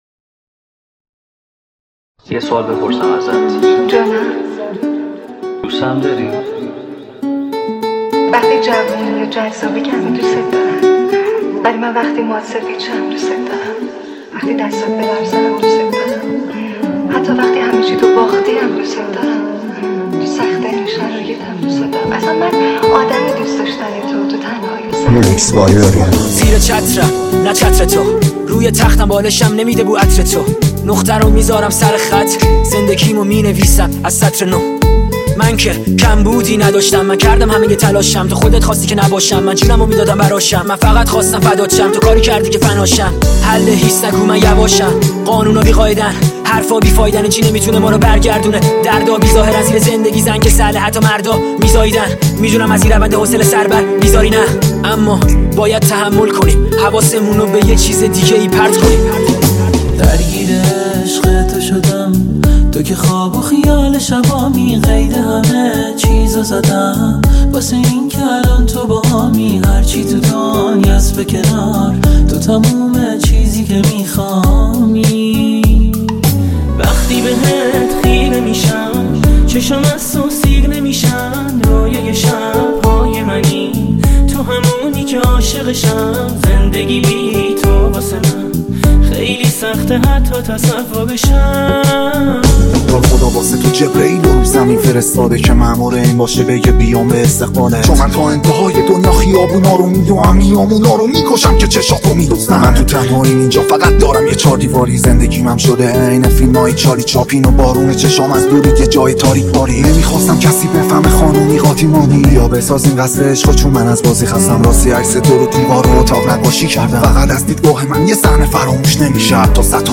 ریمیکس رپ ترکیبی